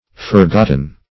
Forgotten \For*got"ten\,